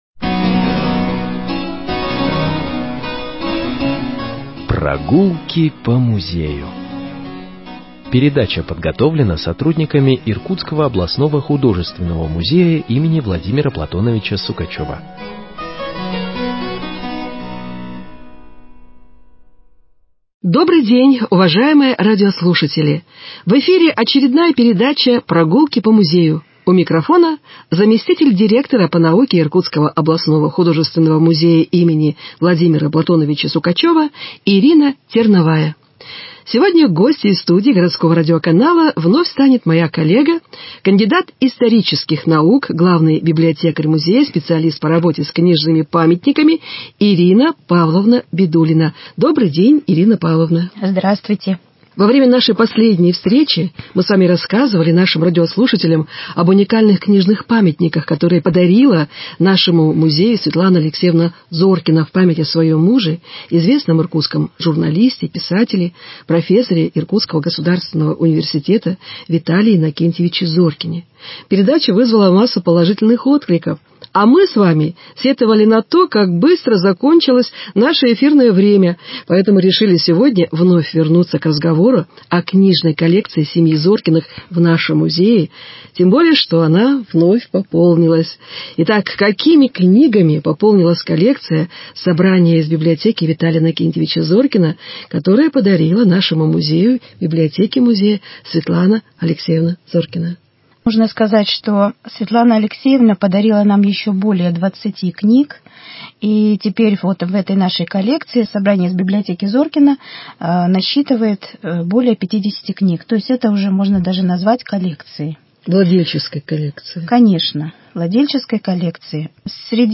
Передача из авторского цикла